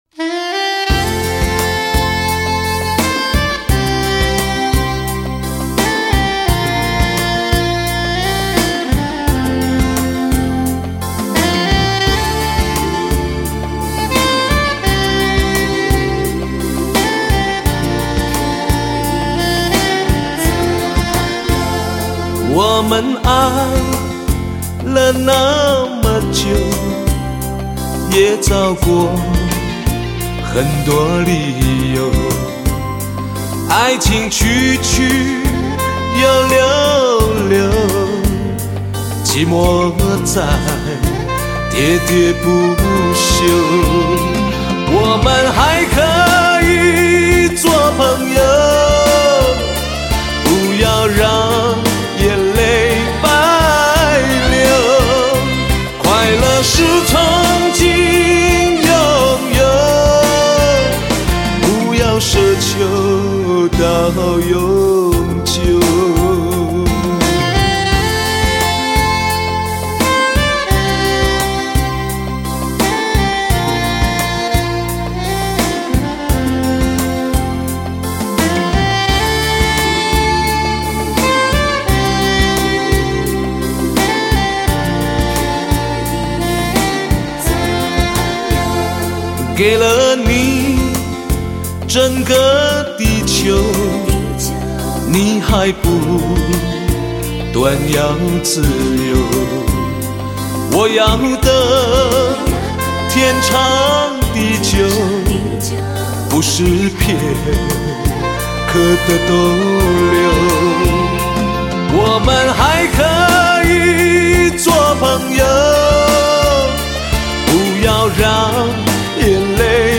铁汉金腔伴柔情 流行金曲新里程